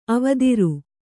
♪ avadiru